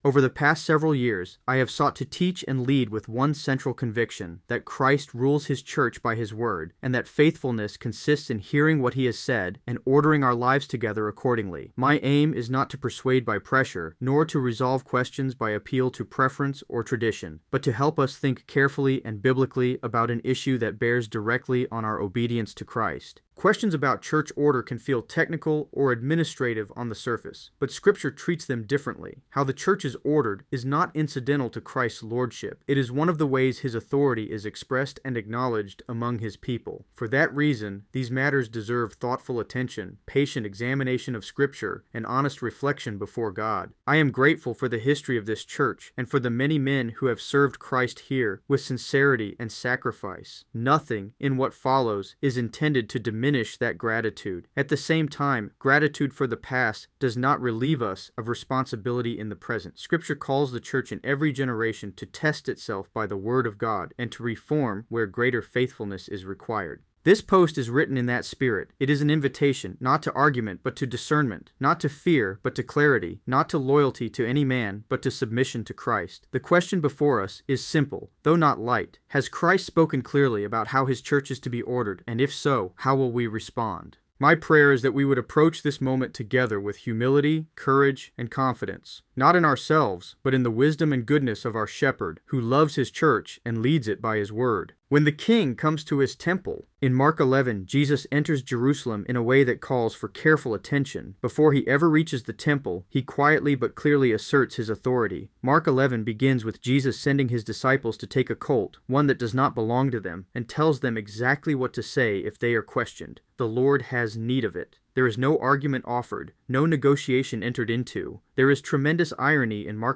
Note—if reading isn’t your thing (or if you just like listening while driving or doing dishes), I also put together an audio version of the blog post. It was generated using AI and modeled after my own voice, so it sounds somewhat like me—close enough, at least…or maybe a bit strange 🙂